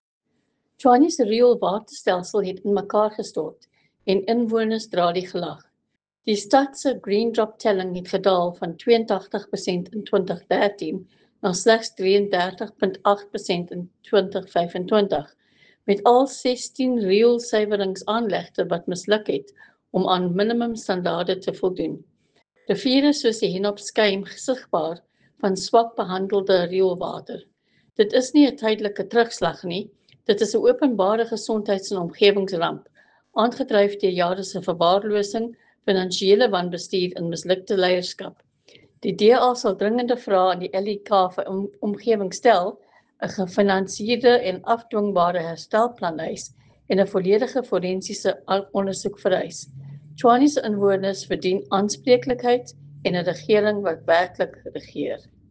Afrikaans soundbites from Leanne De Jager MPL.